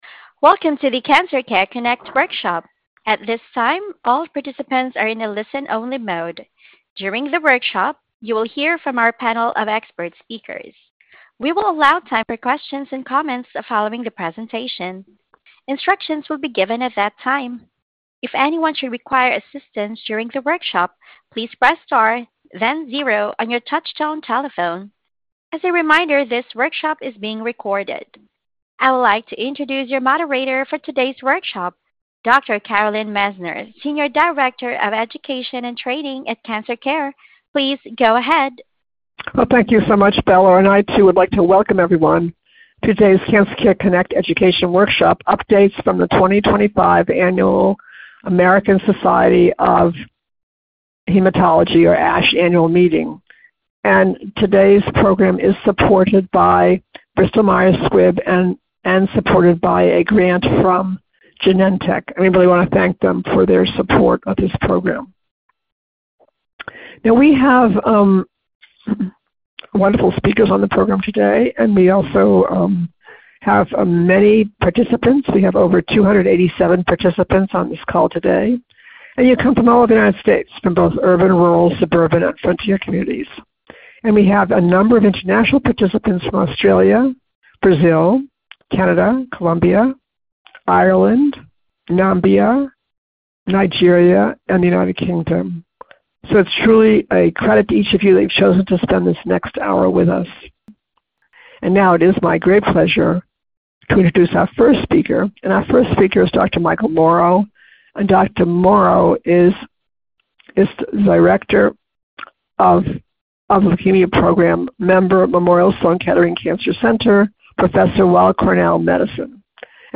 Questions for Our Panel of Expert Speakers